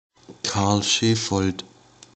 Karl Schefold (Austrian German: [ˈʃeːfɔld]